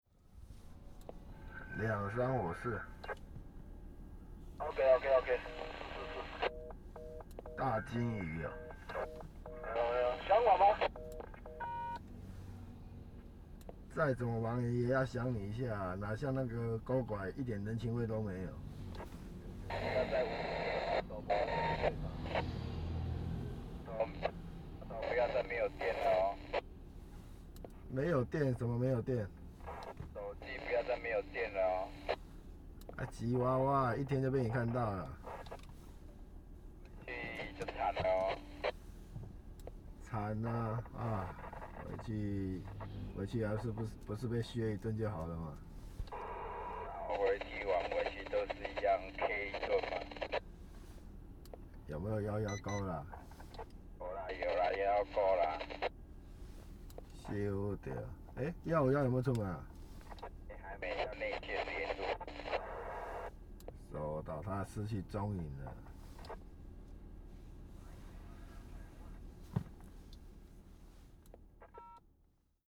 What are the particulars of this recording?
Tags: Travel Taiwan Taipei Sounds of Taiwan Vacation